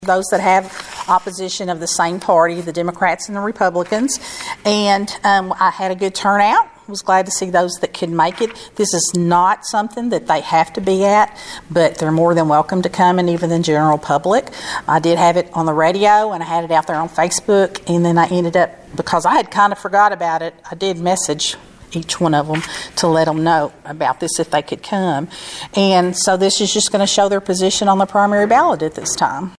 O’Bryan explained to Your WEKT News Edge why the ballot drawing is held before each election.